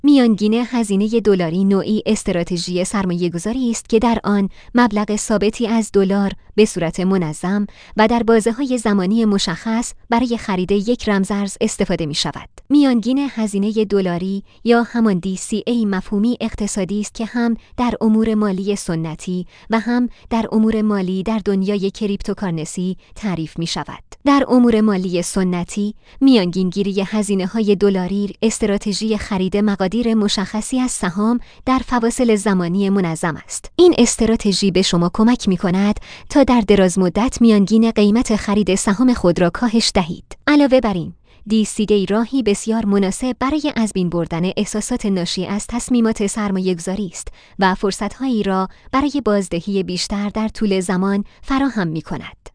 کاربر گرامی این فایل صوتی، با کمک هوش مصنوعی فارسی، ساخته شده است.